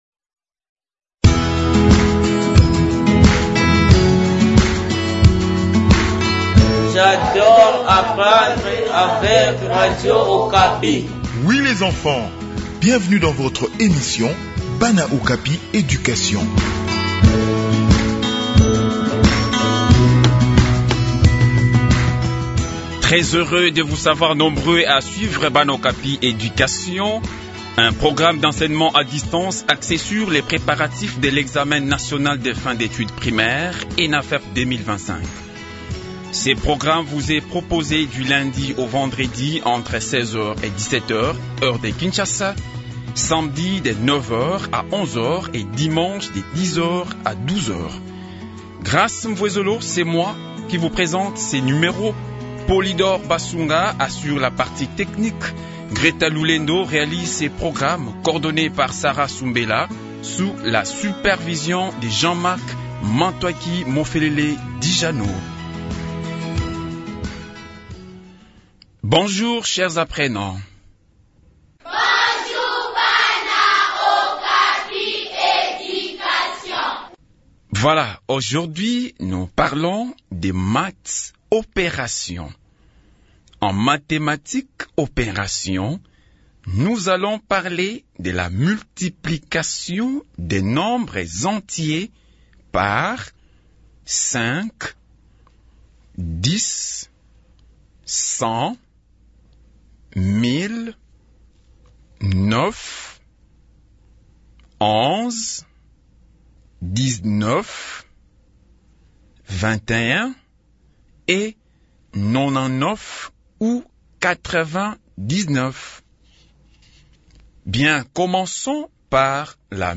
Préparation des examens nationaux : Leçon sur la Multiplication des nombres entiers par 10, 5 et 9